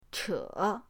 che3.mp3